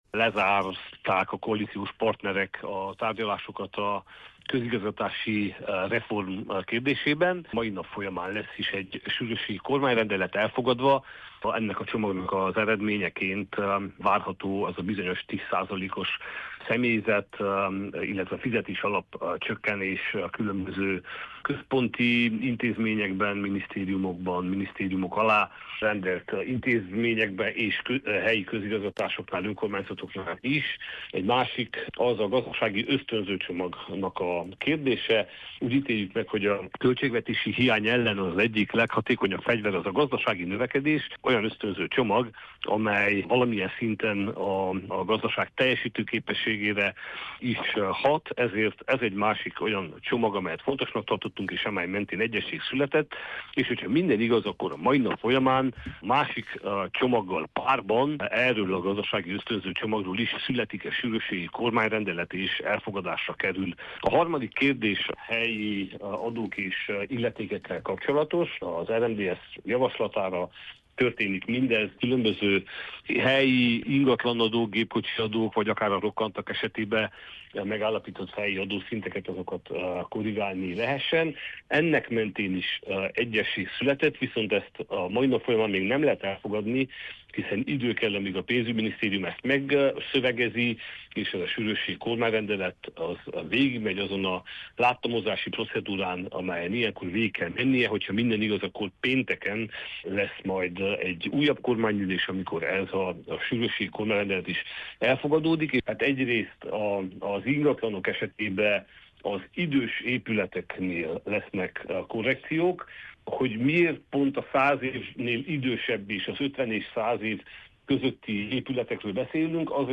Miklós Zoltán képviselőt kérdezte